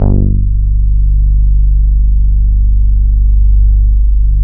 45 BASS   -L.wav